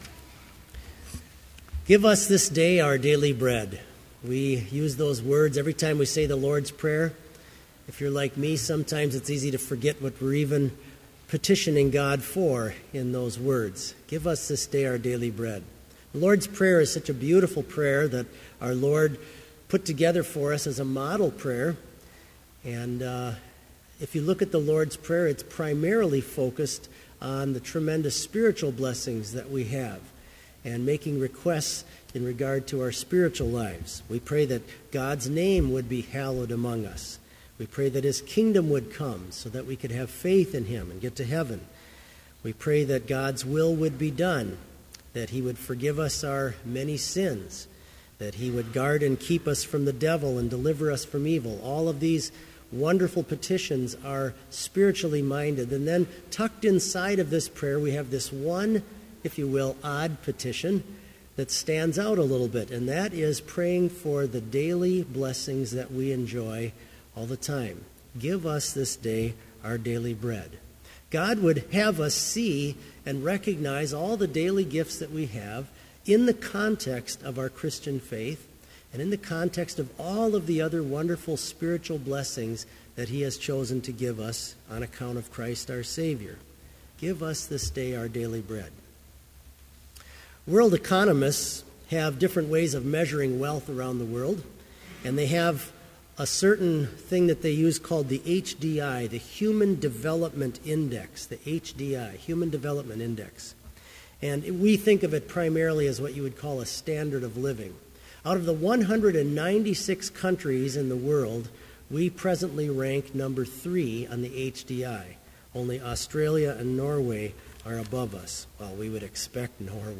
Sermon Only
This Chapel Service was held in Trinity Chapel at Bethany Lutheran College on Wednesday, November 26, 2014, at 10 a.m. Page and hymn numbers are from the Evangelical Lutheran Hymnary.